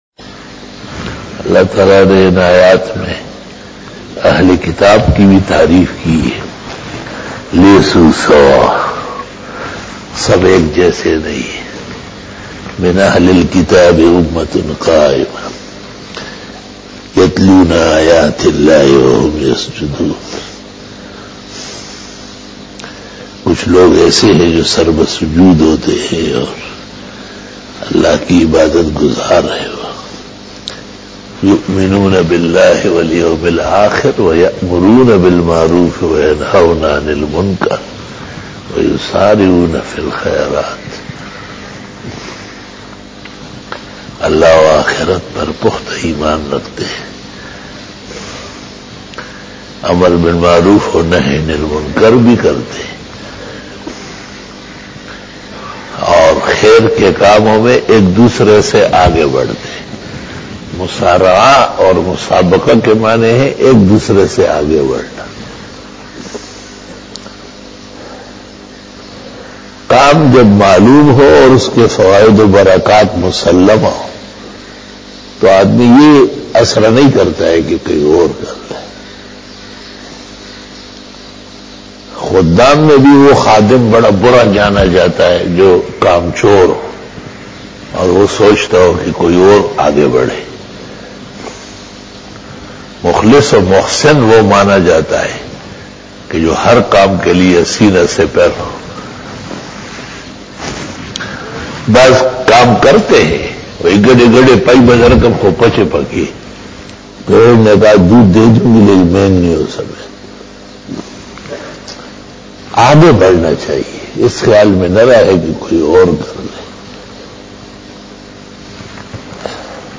After Fajar Byan